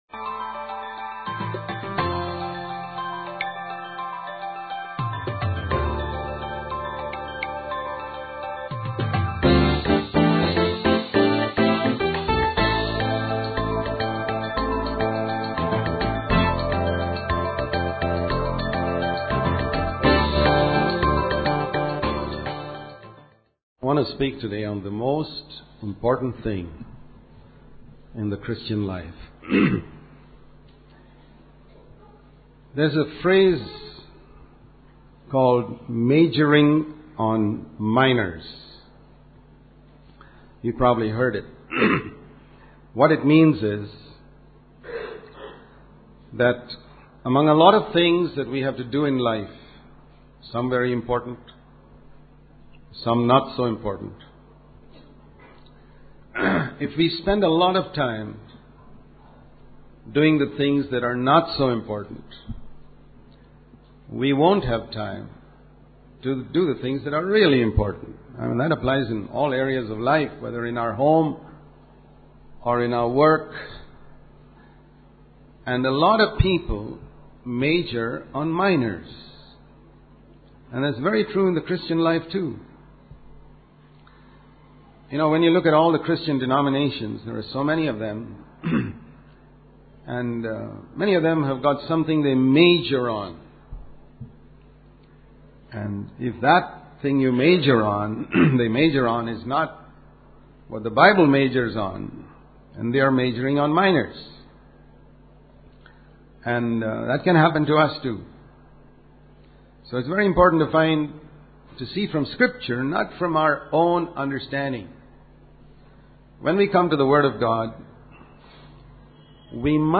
In this sermon, the speaker emphasizes the importance of having a Christlike attitude and values.